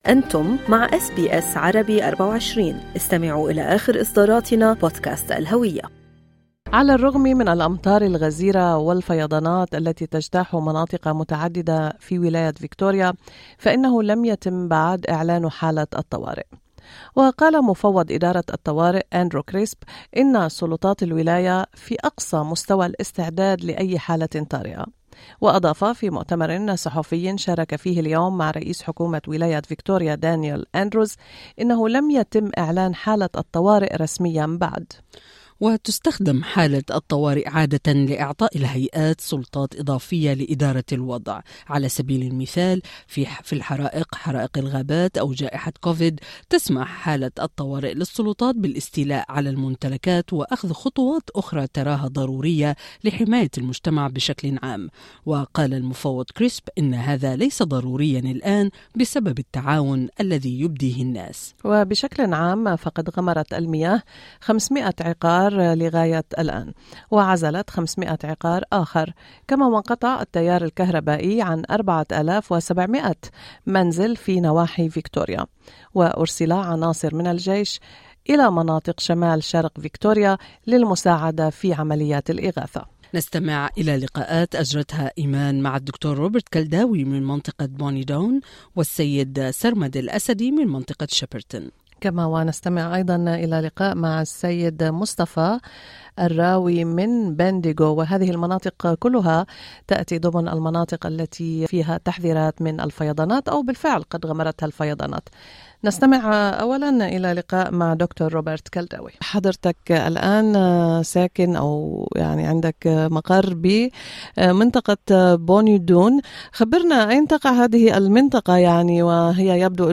أخطر فيضانات تضرب ولاية فيكتوريا منذ 2011: شهادات حية من مناطق ملبورن المغمورة بالمياه